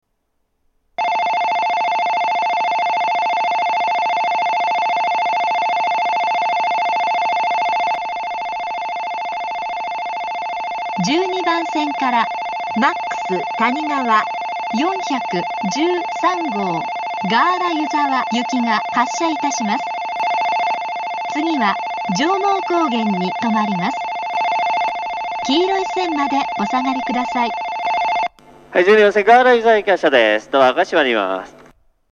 在来線では全ホーム同じ発車メロディーが流れますが、新幹線ホームは全ホーム同じ音色のベルが流れます。
１２番線発車ベル 主に上越新幹線が使用するホームです。
Ｍａｘたにがわ４１３号ガーラ湯沢行の放送です。